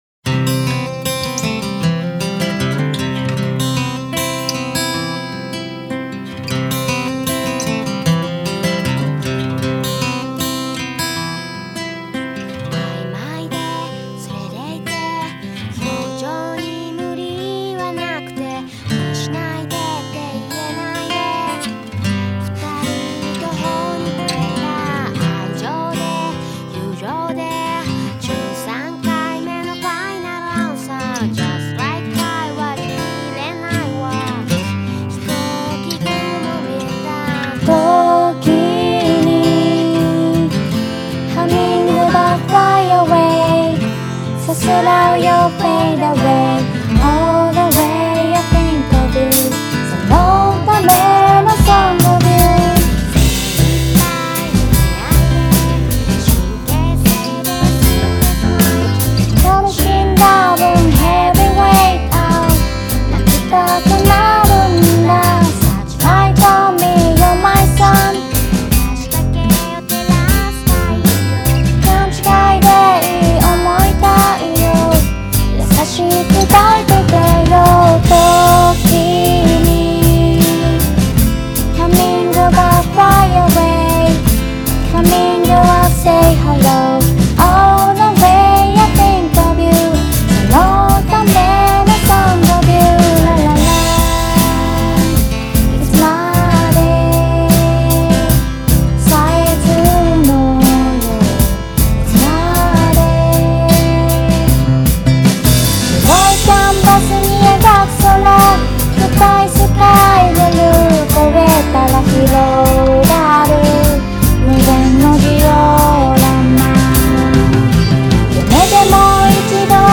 青森県津軽地方のアマチュアバンド！
ハミングバードハモリ練習_0.mp3